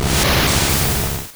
Cri de Kadabra dans Pokémon Rouge et Bleu.